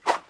Whoosh2.mp3